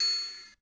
SE2 Ding
cheap cheap-synth children chillwave drum drum-machine fx home sound effect free sound royalty free Nature